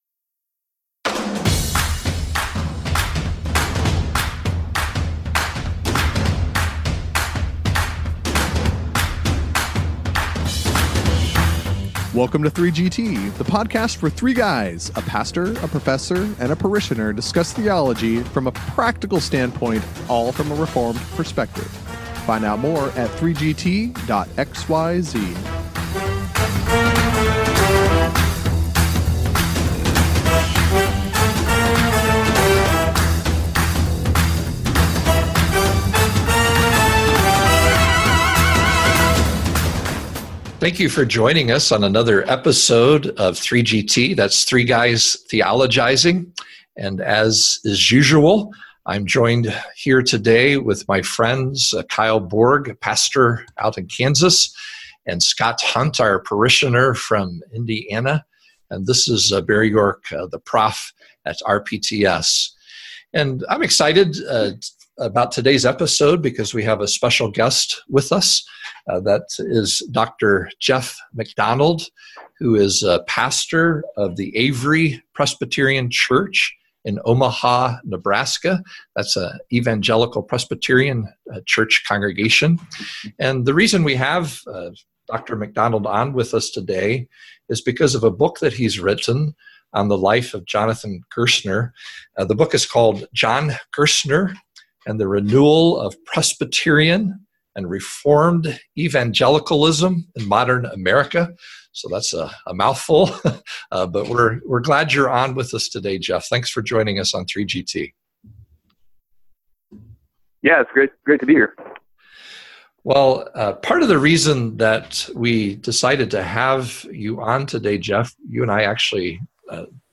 Episode 132: Interview